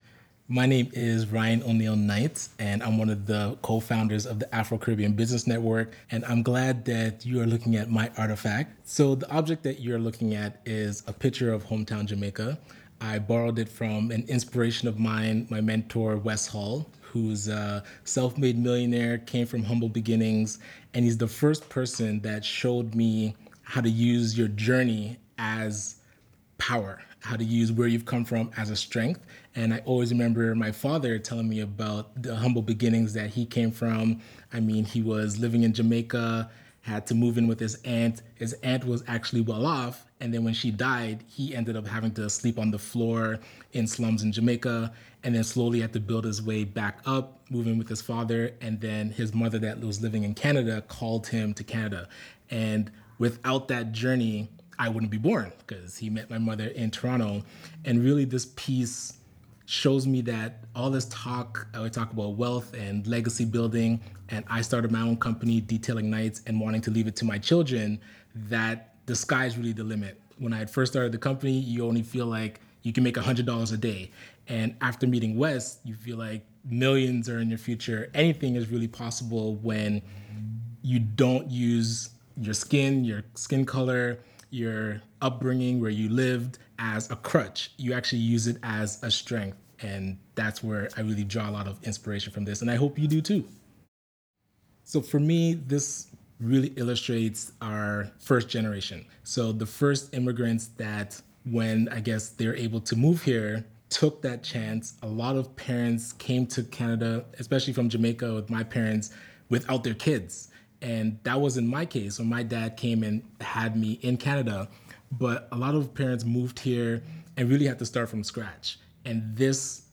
In the interviews below, the curators speak about the significance of their chosen object.